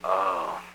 moan
Category: Comedians   Right: Personal